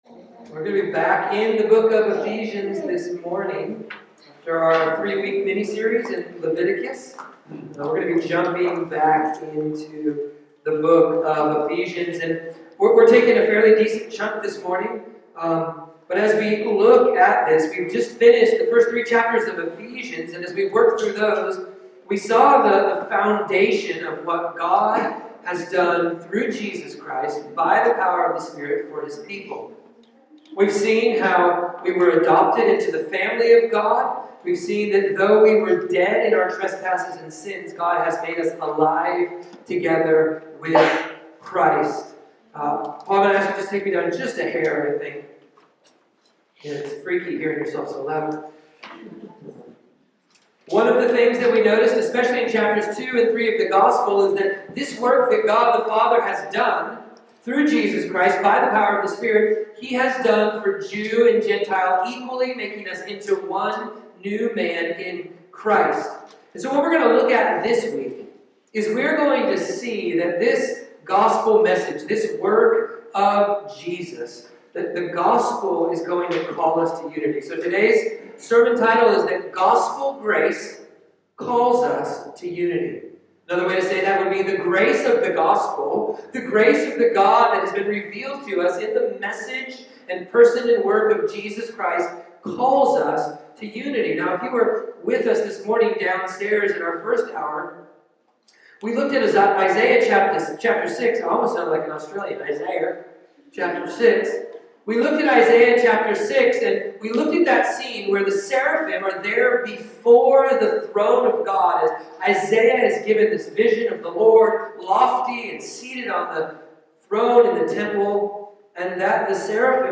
Ephesians-4.1-16-Gospel-Unity-Audio-Sermon.mp3